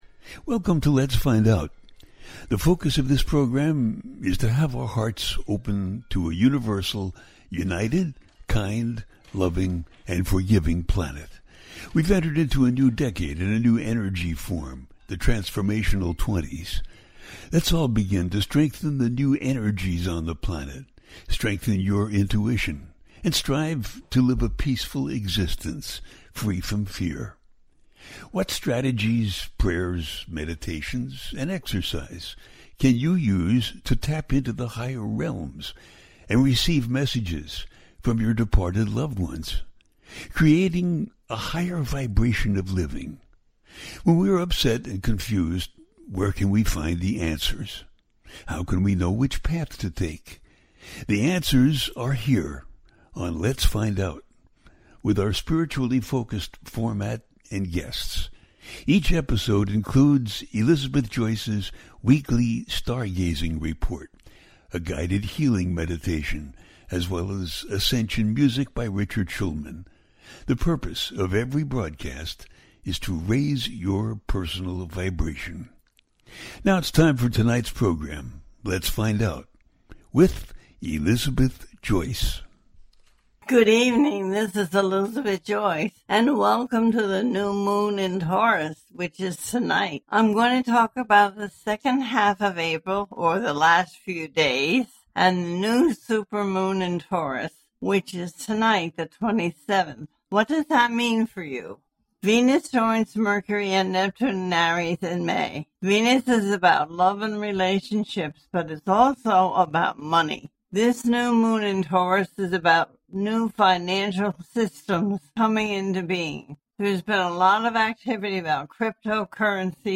New Moon In Taurus and Powerful Collective Energy In May - A teaching show
The listener can call in to ask a question on the air.
Each show ends with a guided meditation.